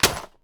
gen_hit.ogg